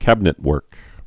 (kăbə-nĭt-wûrk)